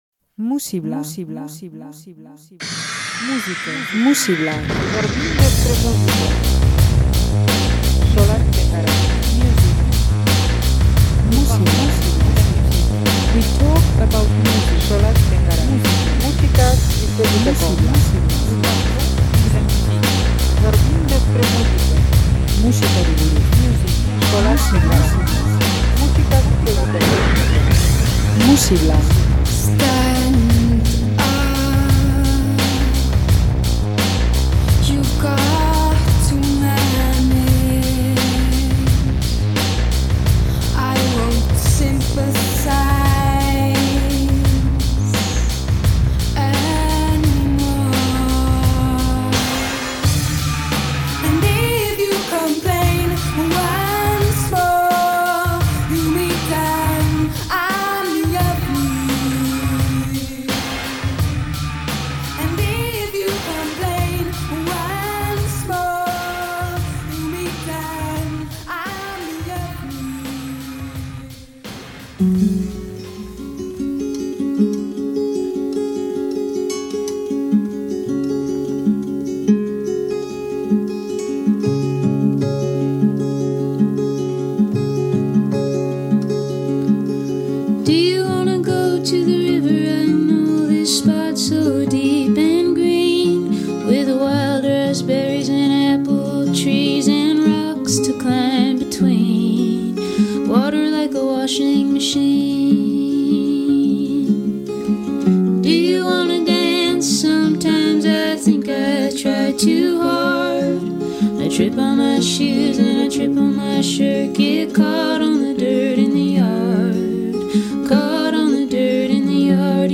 Ukitu industrialak eta ahots garbiak.